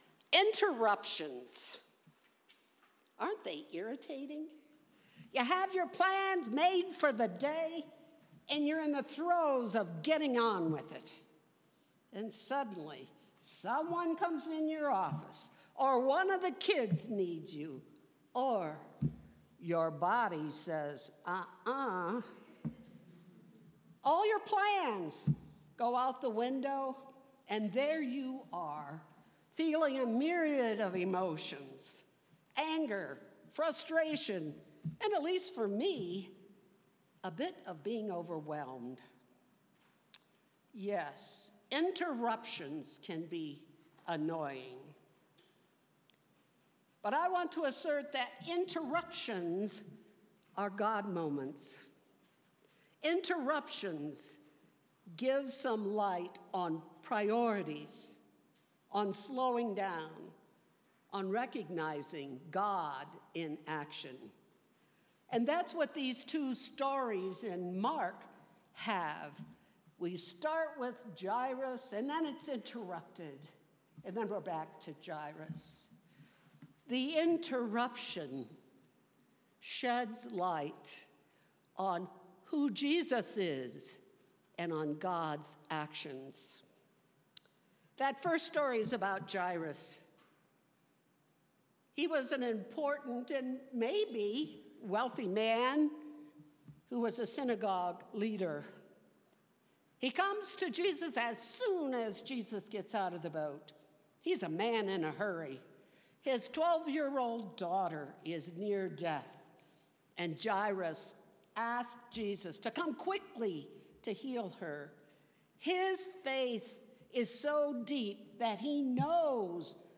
Passage: Mark 5:21-43 Service Type: Sunday Morning